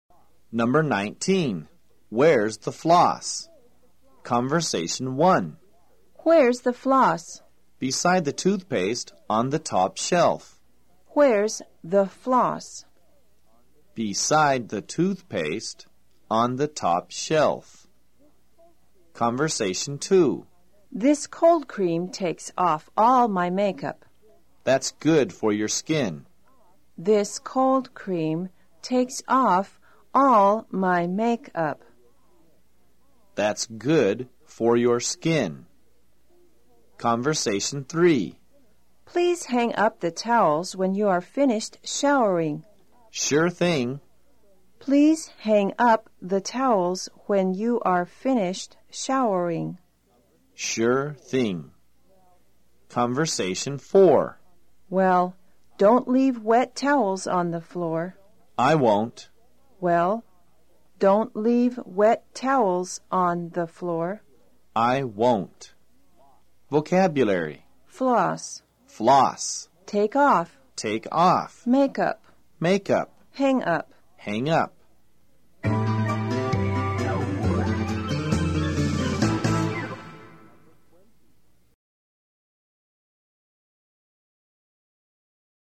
在线英语听力室快口说英语019的听力文件下载,快口说英语的每一句话都是地道、通行全世界的美国英语，是每天24小时生活中，时时刻刻都用得上的美语。